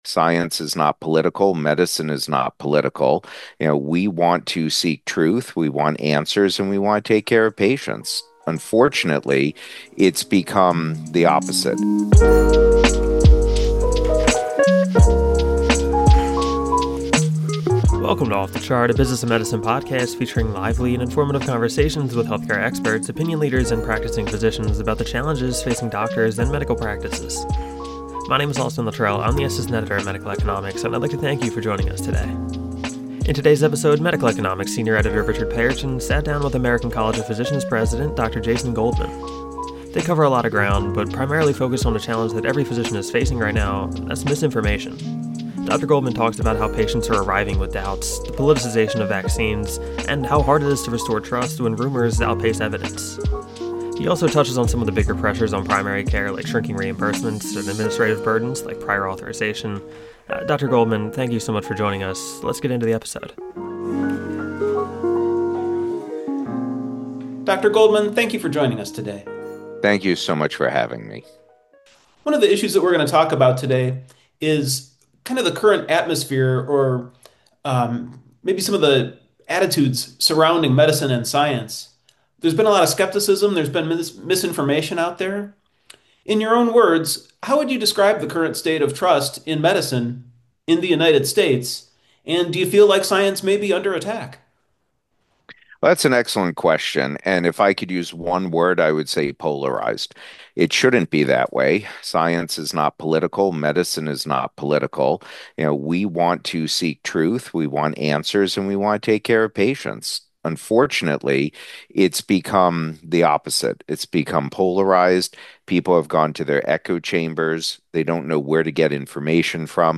American College of Physicians President Jason Goldman, M.D., MACP joins the show to talk about one of the most difficult realities in clinical practice today: medical misinformation.